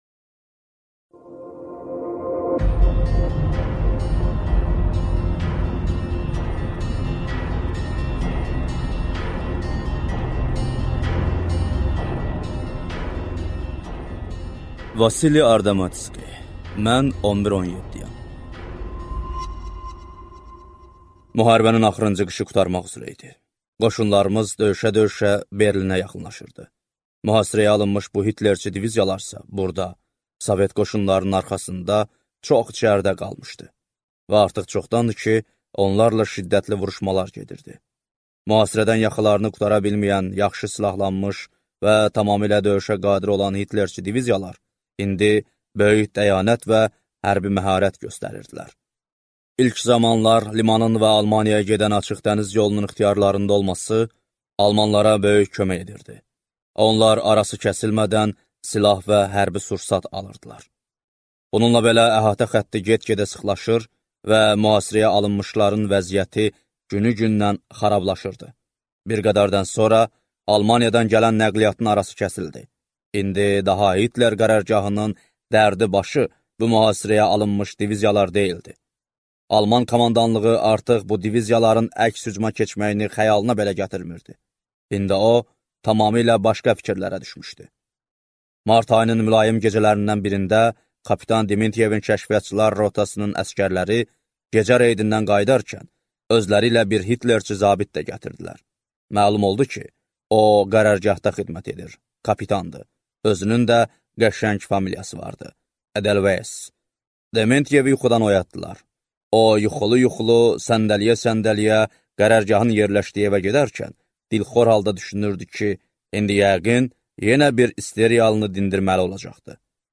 Аудиокнига Mən 11-17-yəm | Библиотека аудиокниг